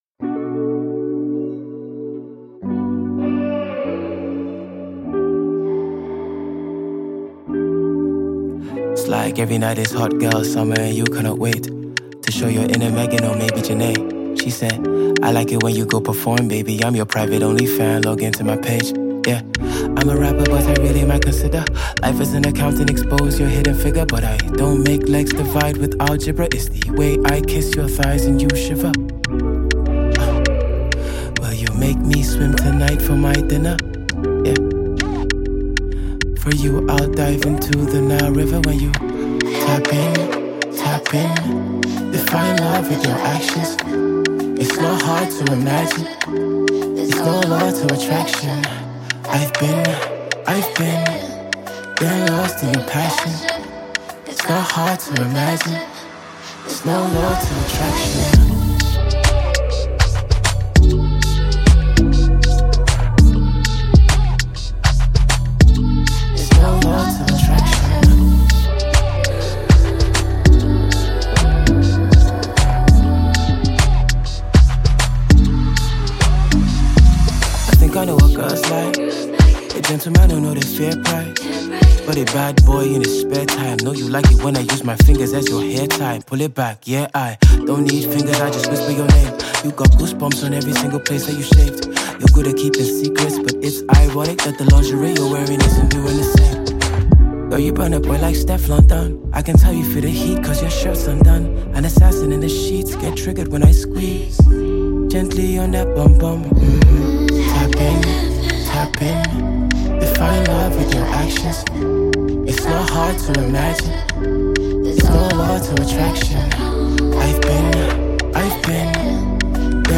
Nigeria Talented Rapper